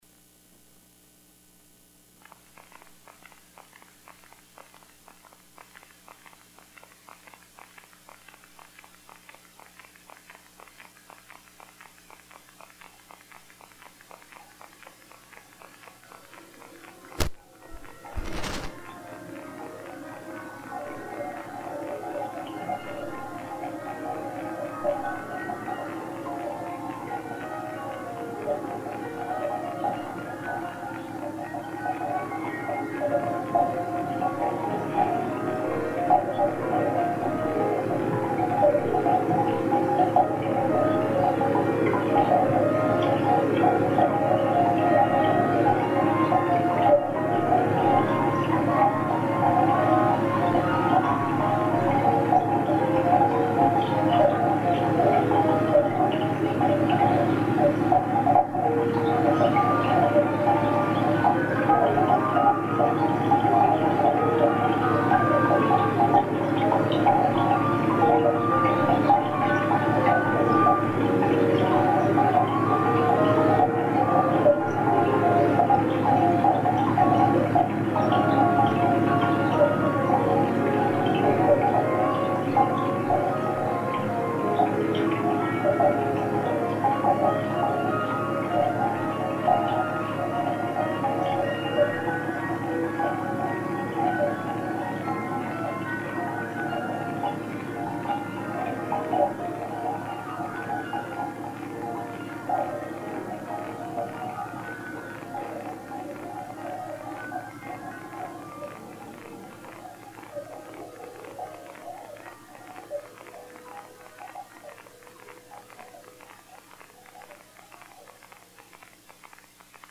Water Dirges, 1983, Chicago, sound art